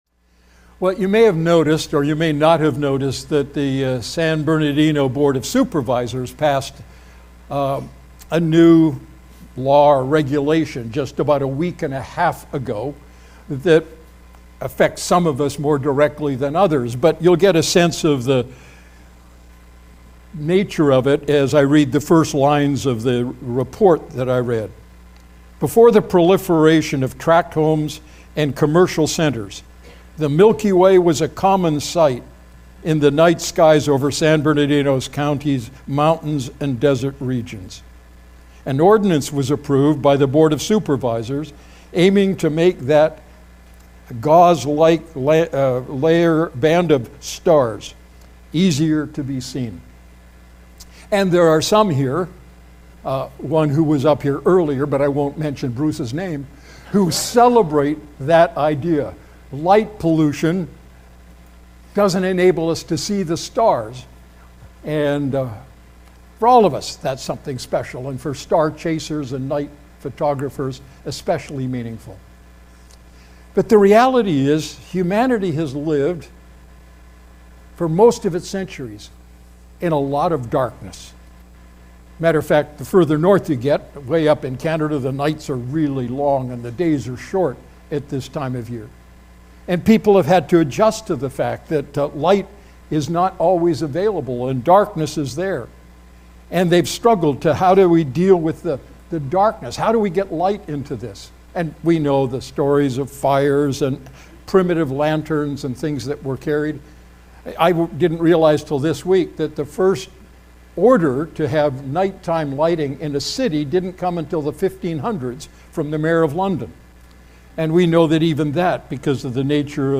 Sermon Archive | Redeemer Fellowship